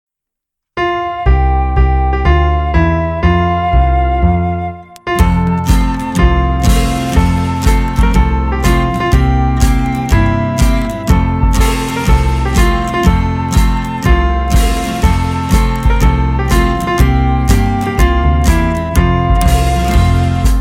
Praise Song for Children